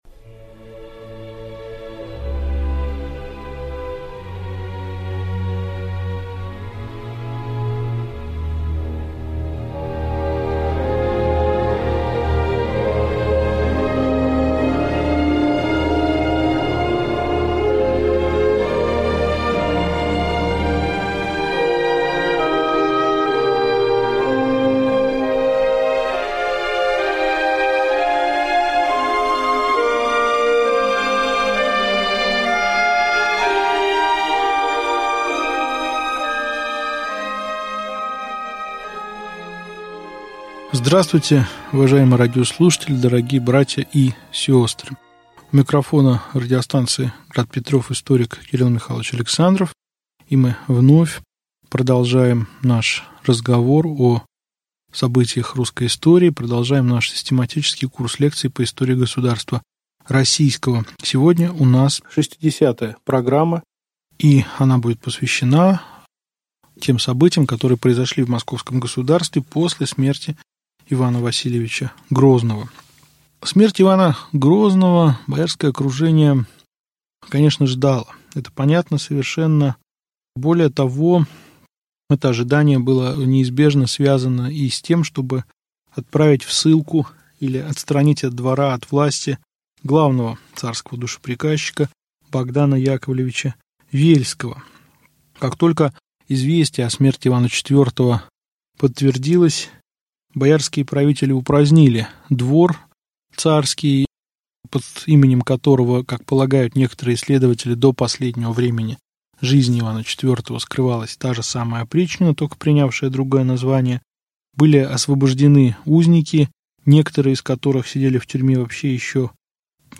Аудиокнига Лекция 60. Царь Феодор Иоаннович. Боярин Борис Годунов | Библиотека аудиокниг